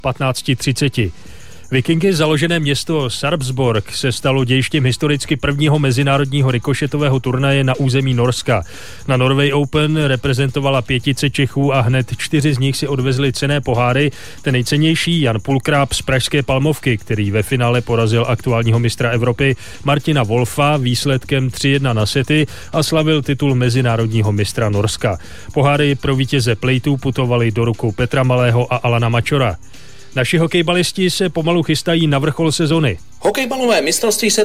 Zpravodajství z radia Beat